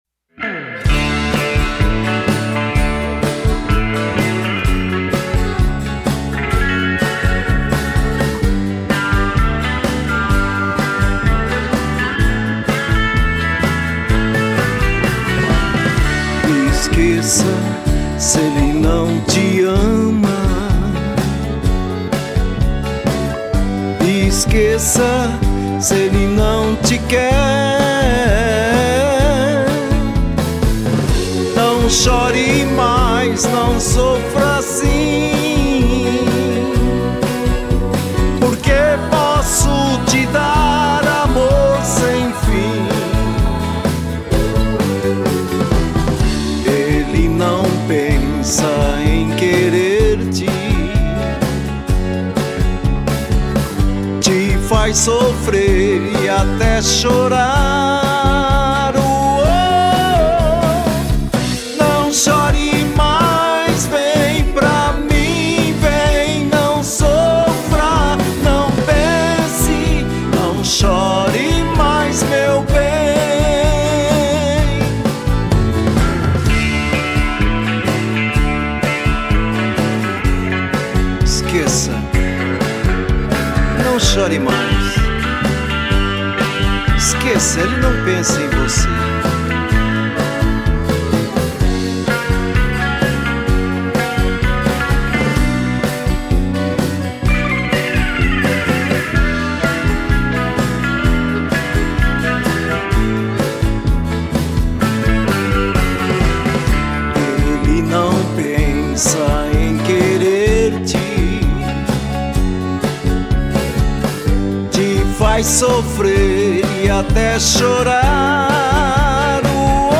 EstiloRegional